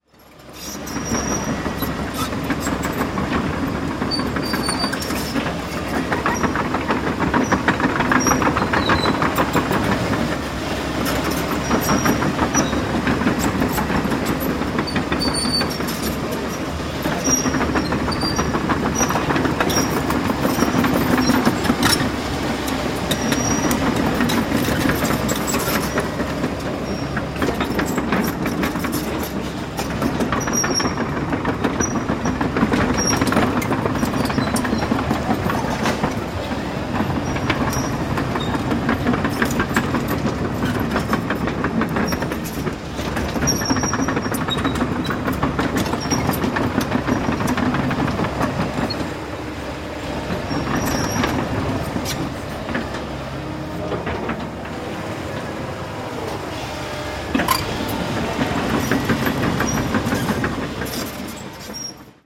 Звуки экскаватора
Гул гусеничного экскаватора